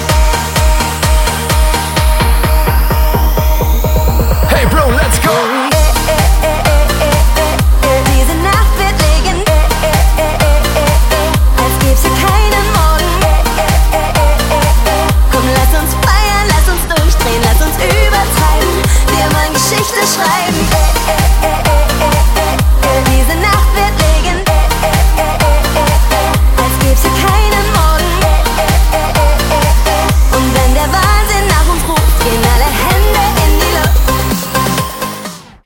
Из клубной музыки